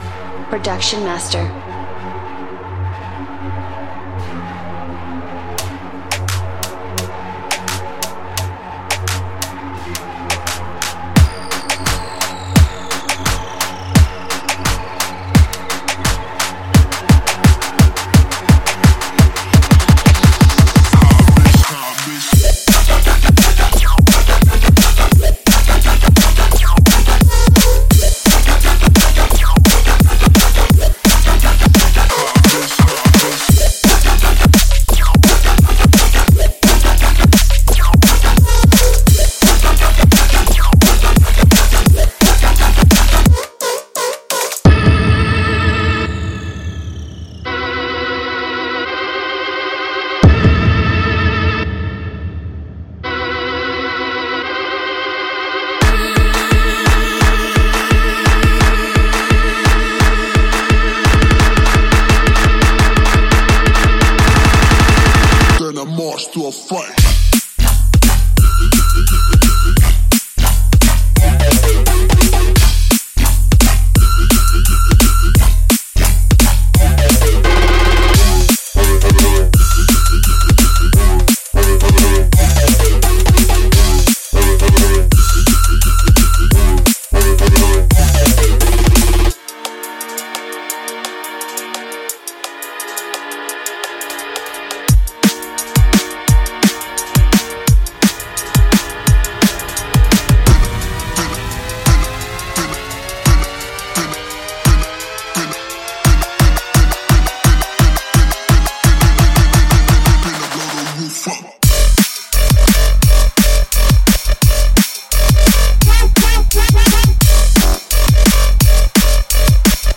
毁灭是完全爆发的世界末日的跳跃混乱!震撼的鼓声和杀手级的声音，卑鄙的咆哮低音，令人讨厌的尖
-..: : : : : :激进的鼓:: : : : : ……
·踩踏地面的脚踢，锋利的帽子和炽烈的军鼓将为您的演奏增添能量!添加大胆的鼓循环以保持游戏的领
….: : : : : : :肮脏的低音:: : : : : …..
.038x Bassline Loops
Fully Mixed And Mastered